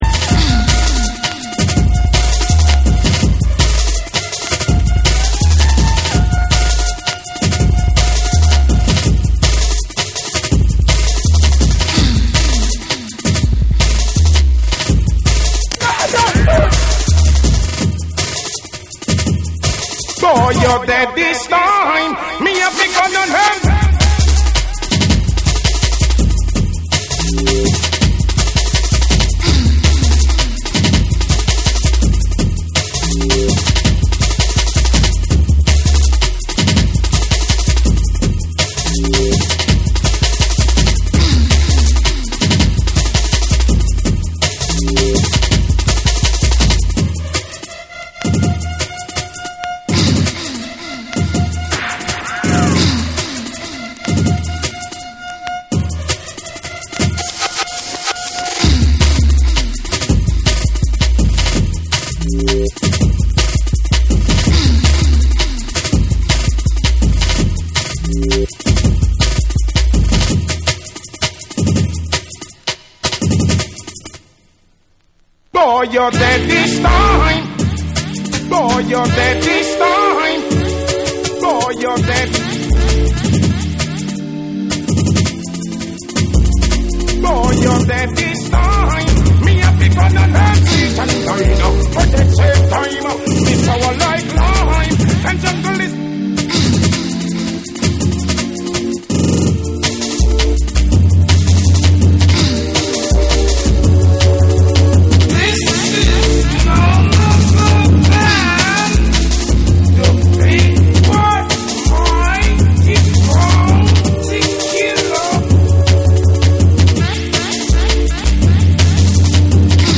バウンシーで重圧なジャングル・ビートにスペイシーなシンセやトリッピーな効果音
JUNGLE & DRUM'N BASS